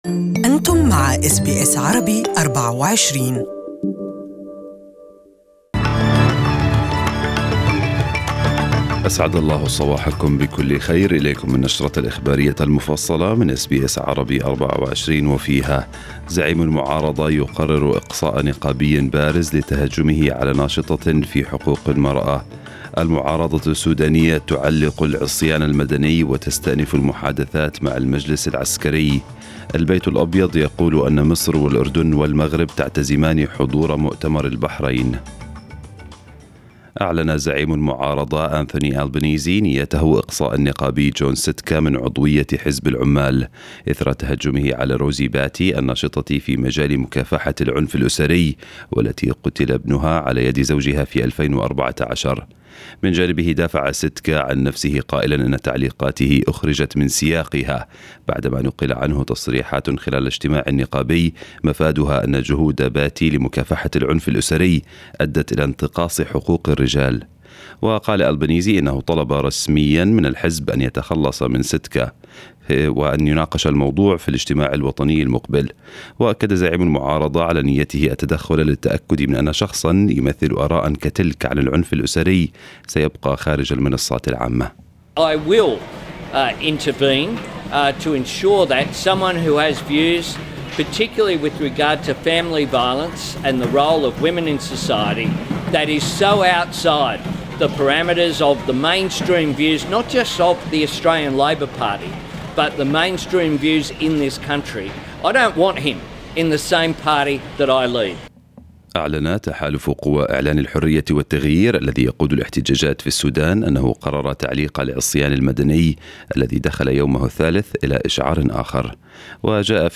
A24 Morning N ews Bulletin Source: Getty